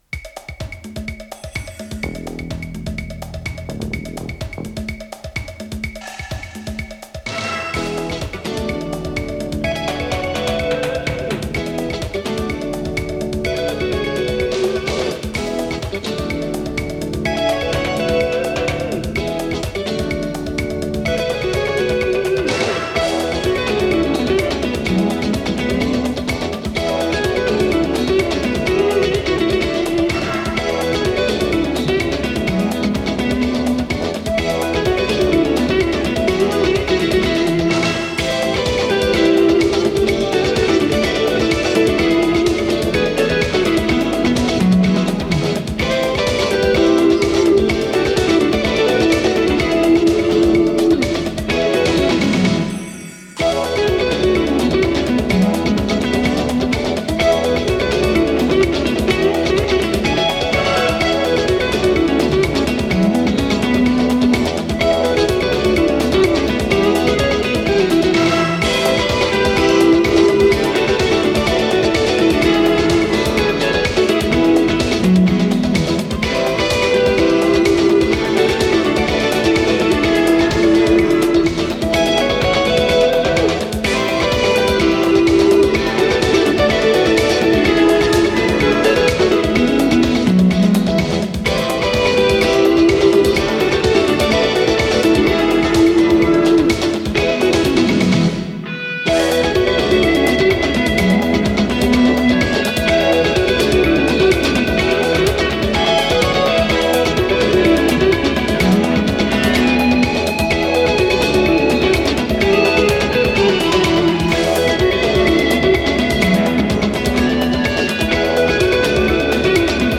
с профессиональной магнитной ленты
Скорость ленты38 см/с
ВариантДубль моно